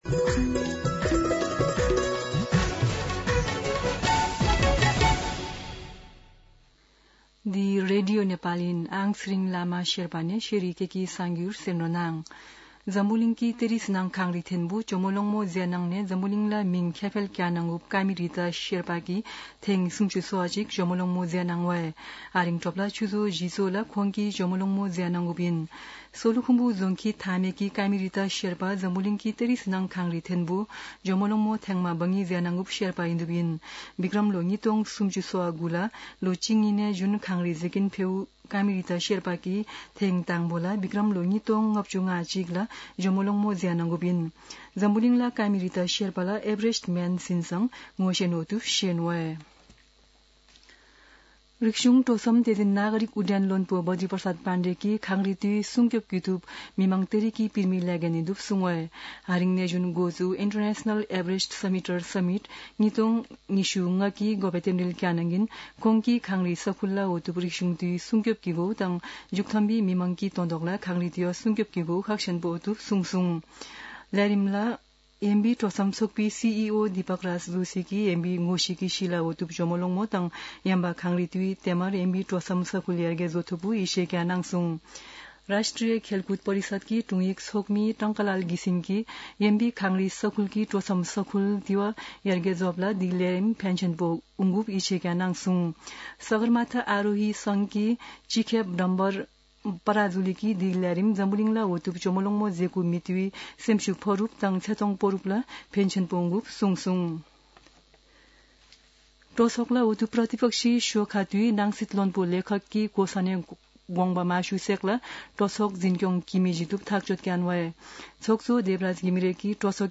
शेर्पा भाषाको समाचार : १३ जेठ , २०८२
Sherpa-News-13.mp3